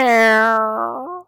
door.mp3